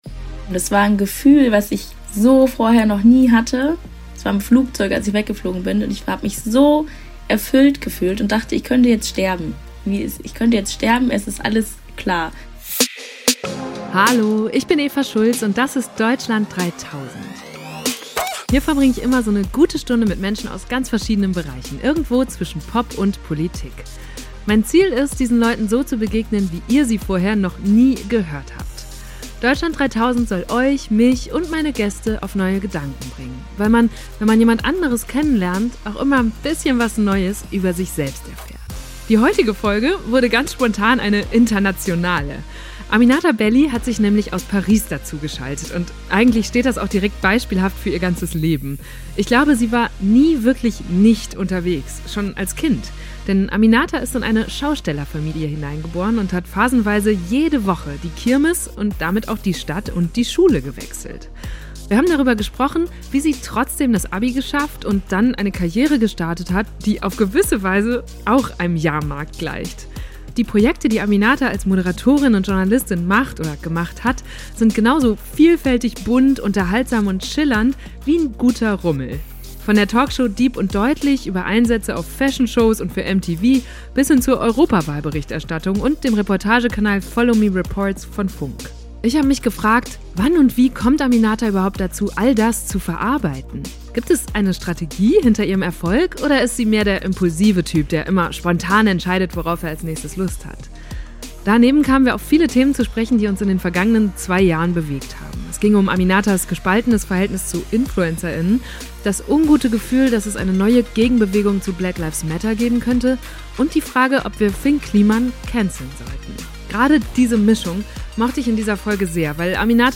Aminata Belli hat sich nämlich aus Paris dazugeschaltet und eigentlich steht das direkt beispielhaft für ihr Leben.